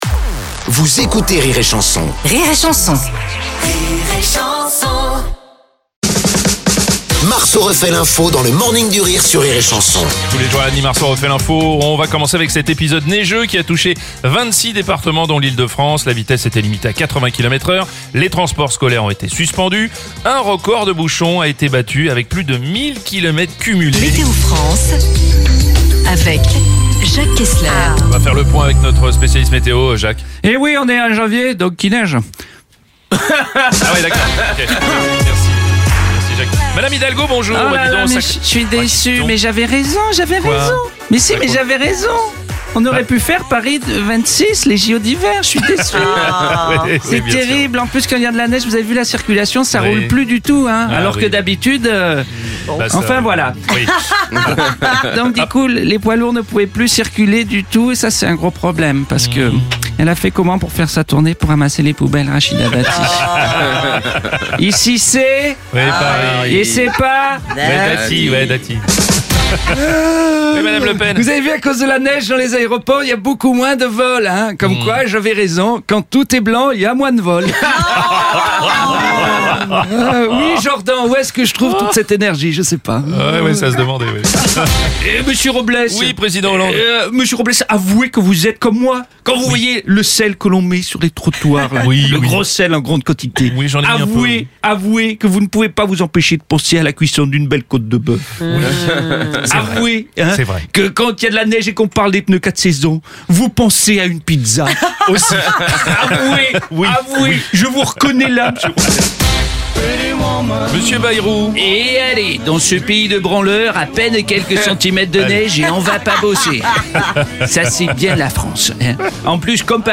Comédie pour toute la famille Divertissement Rire et Chansons France Chansons France Tchat de Comédiens Comédie
débriefe l’actu en direct à 7h30, 8h30, et 9h30.